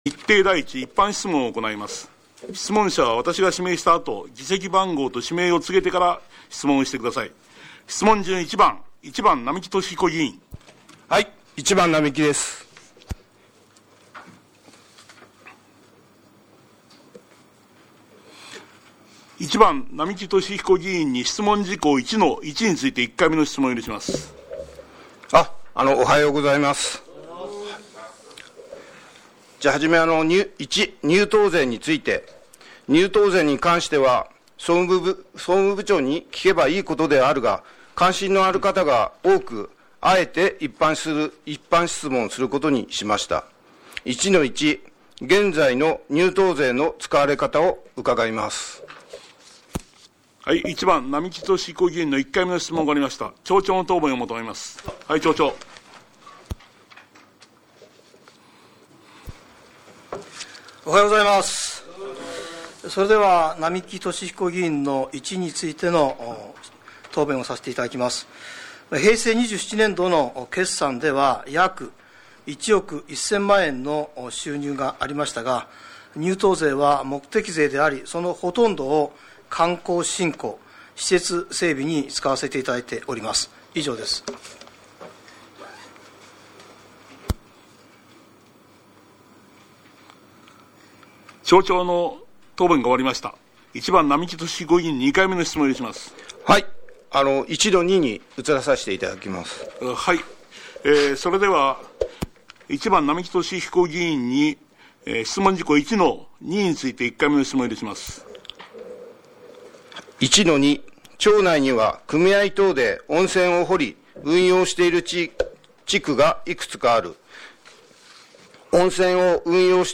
湯沢町議会 ６月定例会
一般質問